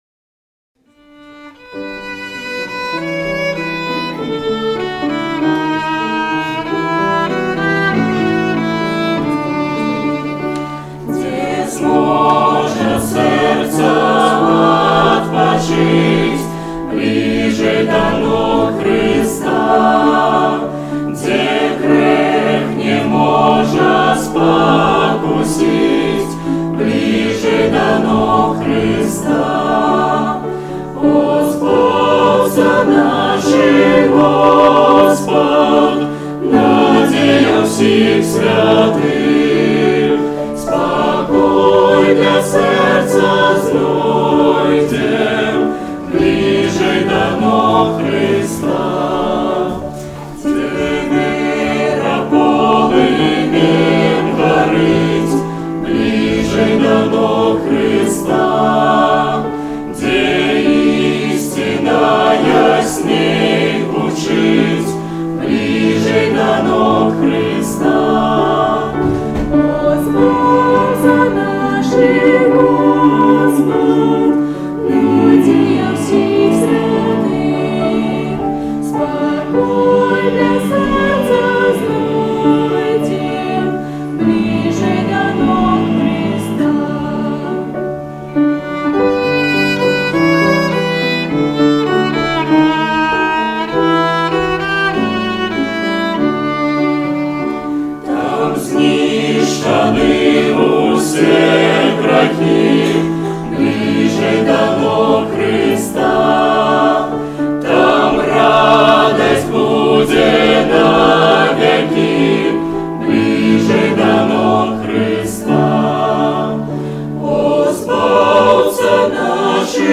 17-09-17 / Блiжэй да ног Хрыста. (Моложёжное прославление)